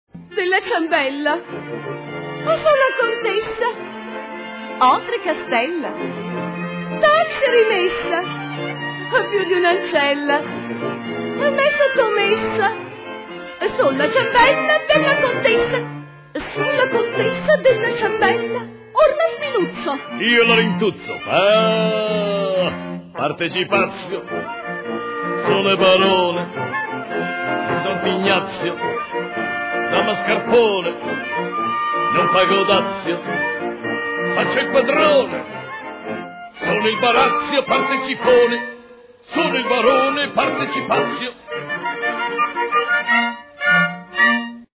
cantano il duetto della Contessa e del Barone